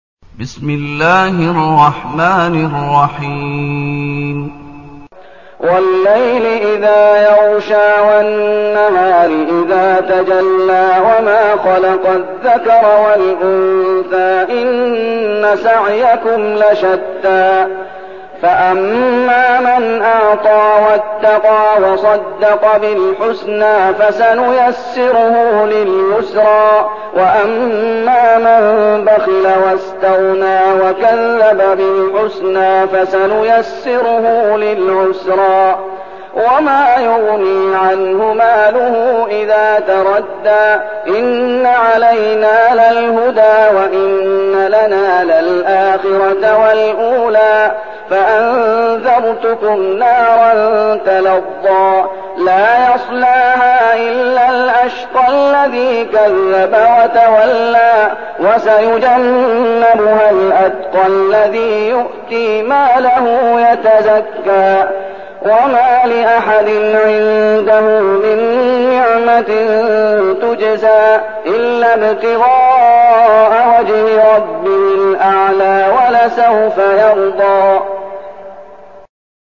المكان: المسجد النبوي الشيخ: فضيلة الشيخ محمد أيوب فضيلة الشيخ محمد أيوب الليل